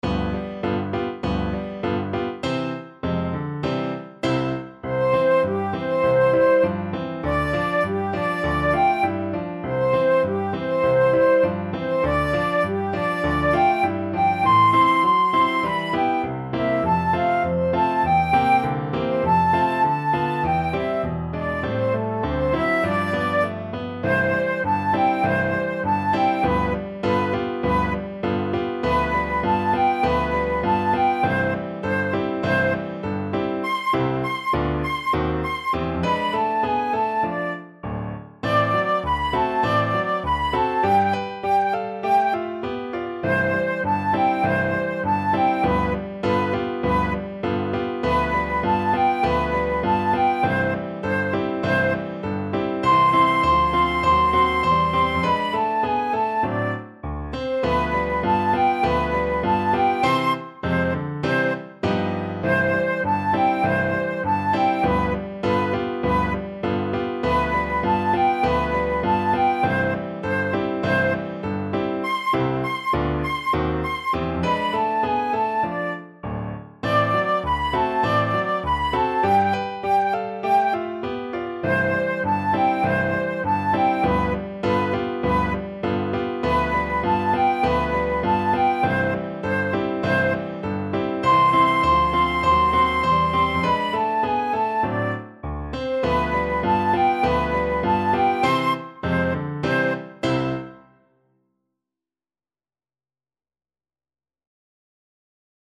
Flute version
Moderato =c.100
Pop (View more Pop Flute Music)